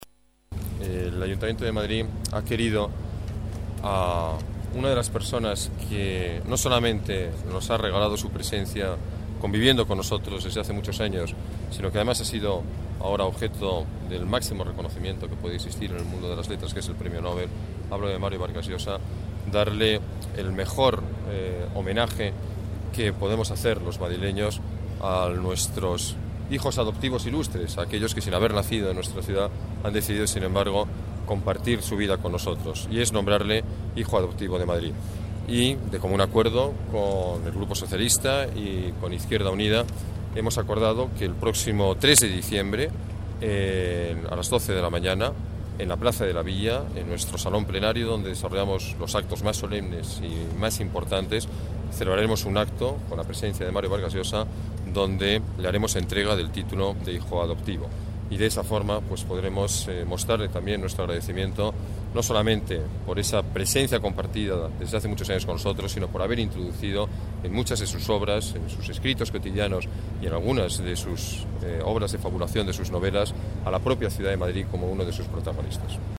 Nueva ventana:Declaraciones del alcalde, Alberto Ruiz-Gallardón